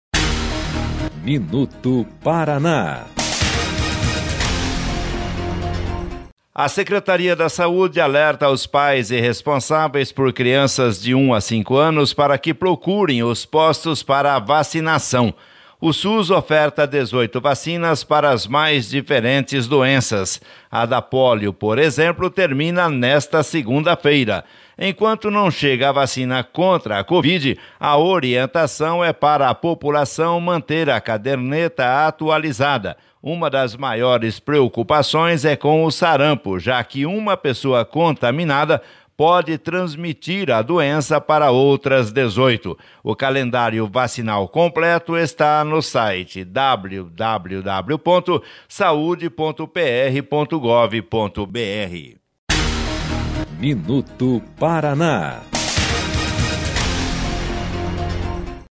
MINUTO PARANÁ - VACINAS PARA CRIANÇAS 27/11/2020 MINUTO PARANÁ : A Secretaria de Estado da Saúde alerta pais ou responsáveis por crianças de 12 meses até cinco anos para que as levem a postos para a imunização. Todas as salas de vacina estão adotando os protocolos de segurança estabelecidos para a prevenção da Covid-19. Repórter: